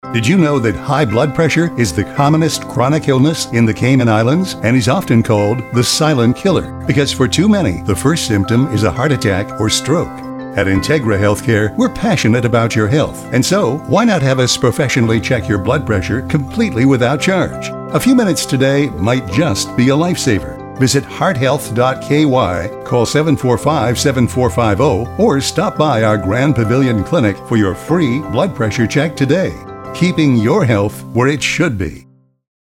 Each advert required its own ‘tone’ and inference, and yet all had to conform to a recognisable style.
TONE-SETTING & CHOICE OF VOICEOVER ARTIST